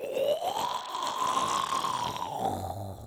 Zombie Voice Pack - Free / Zombie Death
zombie_death_004.wav